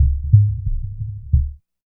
88 HEARTBEAT.wav